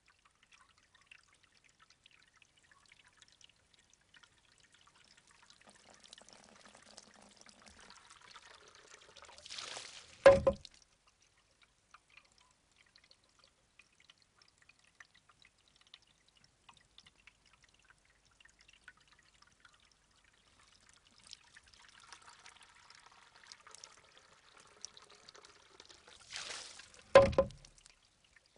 Shishi_odoshi.L.wav